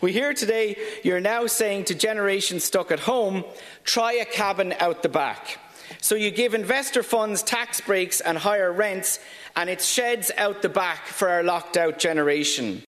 But speaking in the Dáil, Social Democrats TD Rory Hearne criticised the plan: